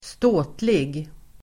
Uttal: [²st'å:tlig]